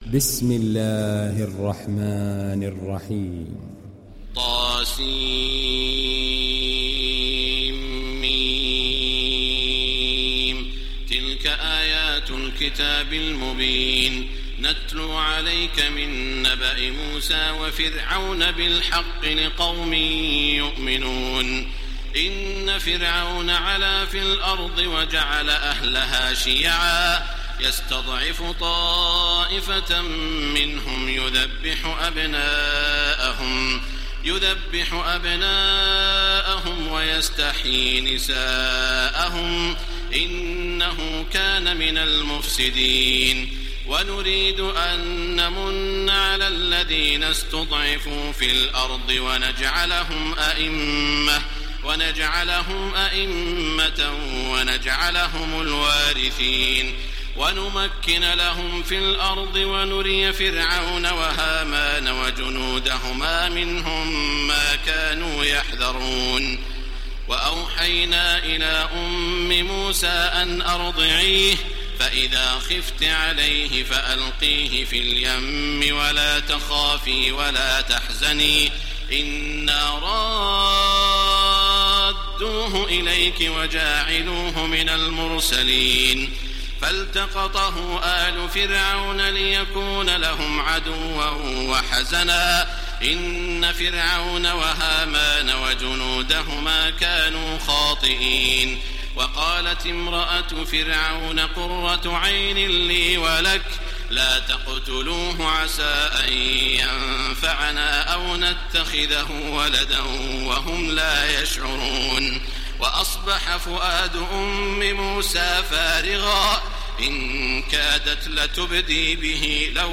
ডাউনলোড সূরা আল-ক্বাসাস Taraweeh Makkah 1430